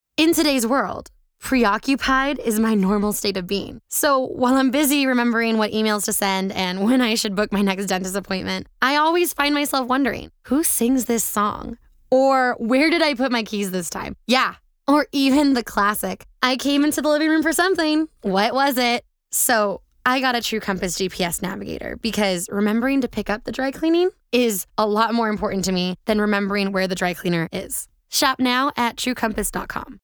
Upbeat, Unique, Fun, Dynamic, Lovable, Girl Next Door
Demo
Location: Burbank, CA, USA Languages: english Accents: standard us | natural Voice Filters: VOICEOVER GENRE ANIMATION 🎬 COMMERCIAL FILTER comedy cool warm/friendly